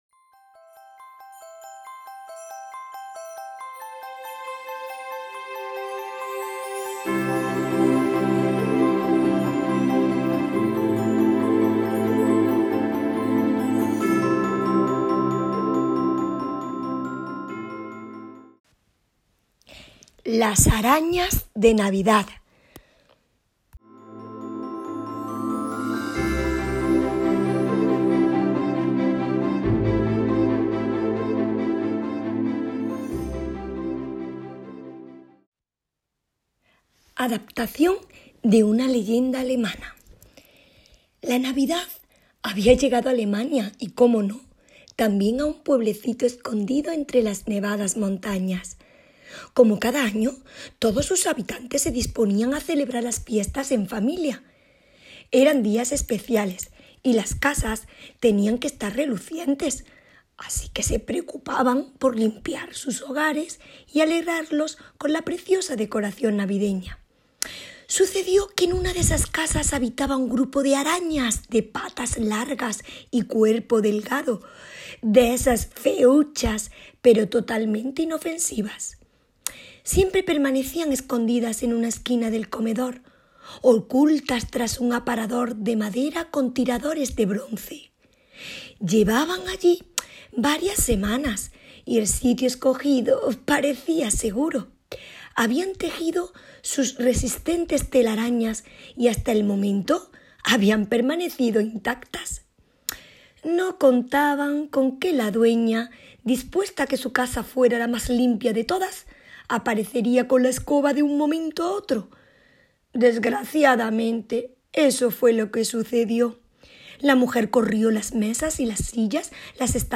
Audio o podcast con alguna lectura propia o ajena
LAS-ARAÑAS-DE-NAVIDAD.LEYENDA.mp3